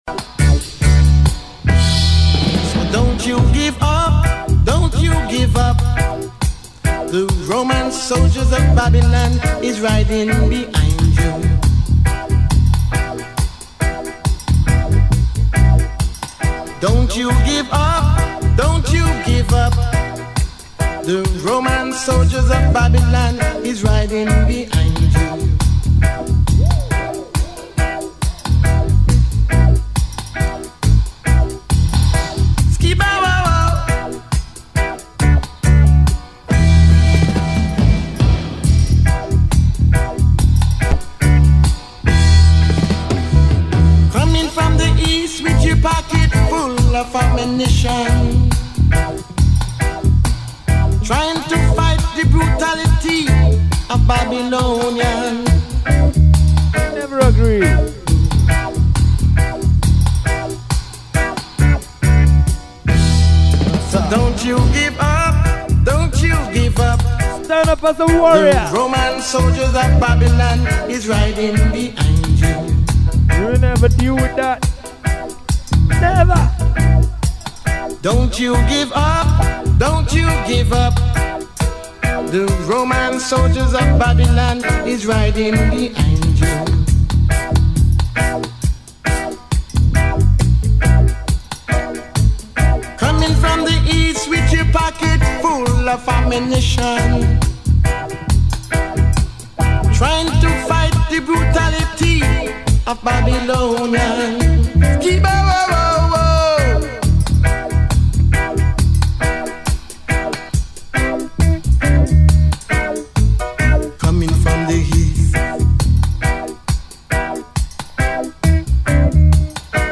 live & direct